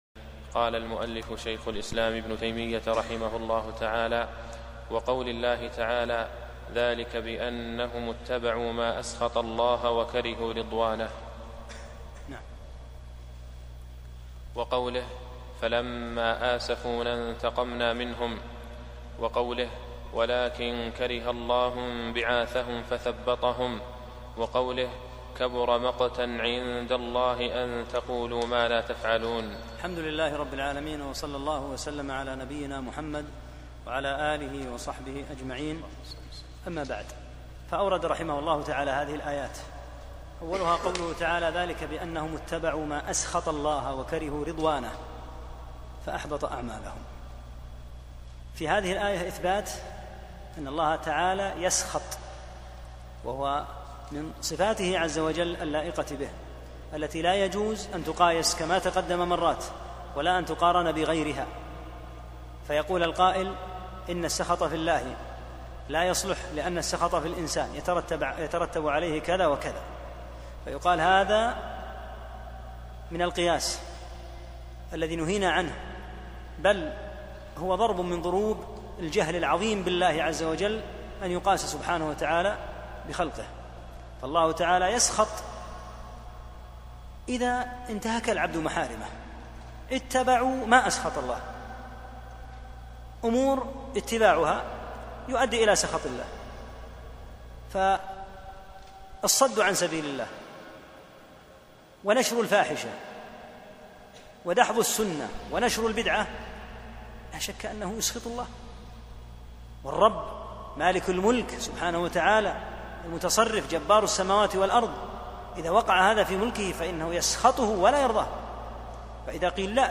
5- الدرس الخامس